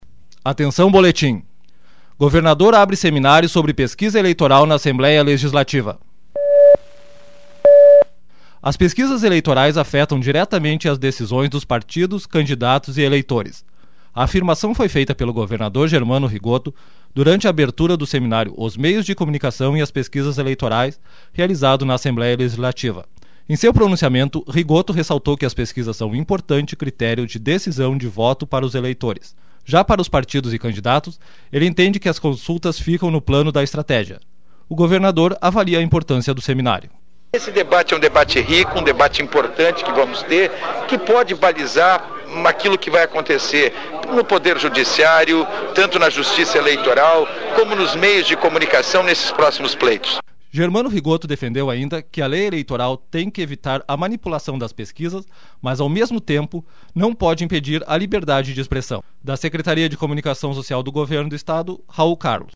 As pesquisas eleitorais afetam diretamente as decisões dos partidos, candidatos e eleitores. A afirmação foi feita pelo governador Germano Rigotto durante a abertura do seminário Os Meios de Comunicação e as Pesquisas Eleitorais, realizado na Assembléia L